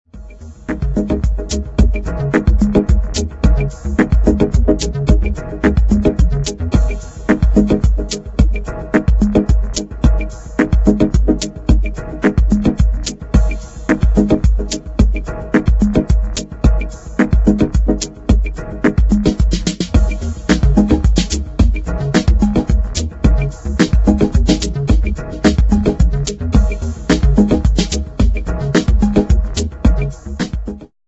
2000 exciting medium instr.